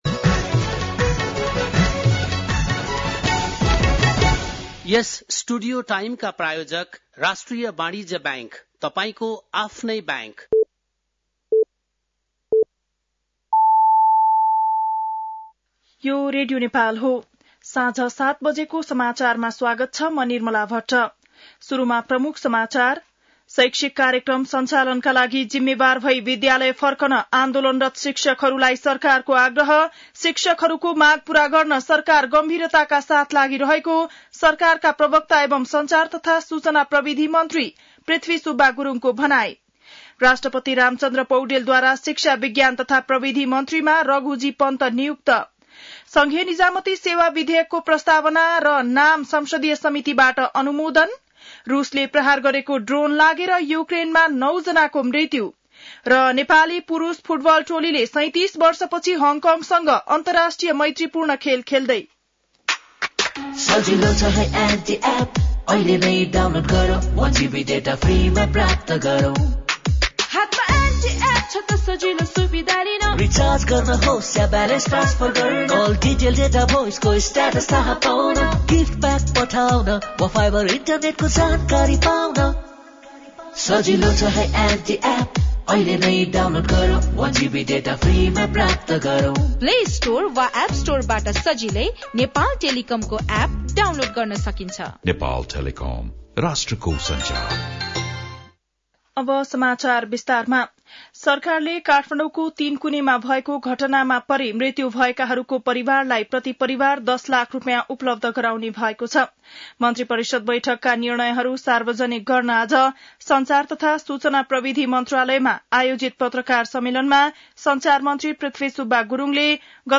बेलुकी ७ बजेको नेपाली समाचार : १० वैशाख , २०८२
7-pm-nepali-news-3.mp3